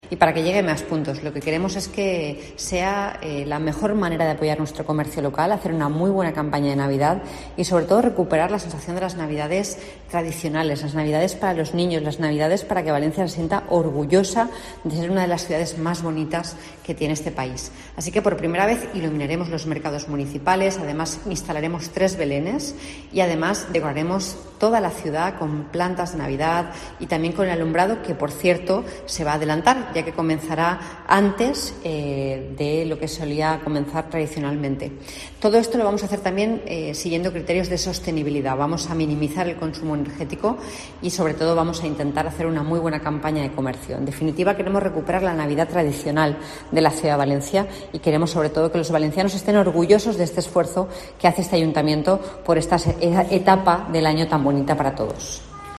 La alcaldesa Mª José Catalá da detalles de la iluminación navideña